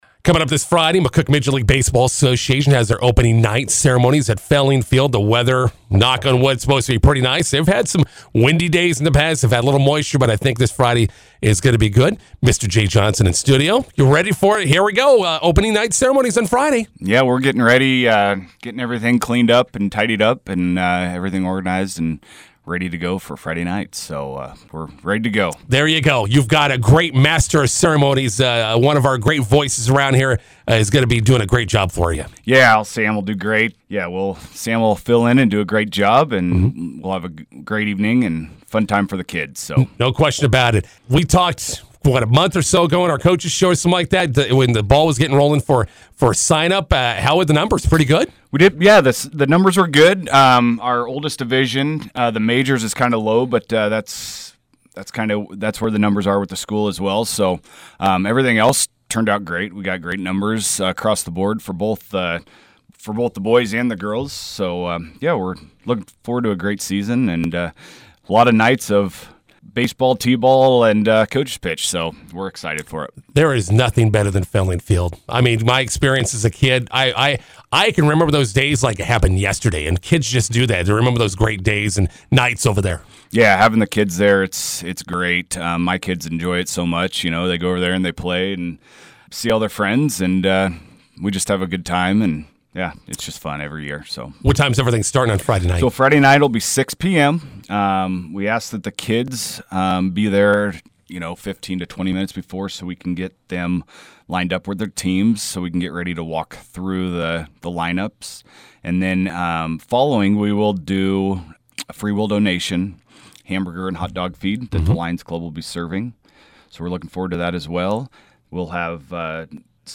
INTERVIEW: McCook Midget League Baseball opening night Friday at Felling Field.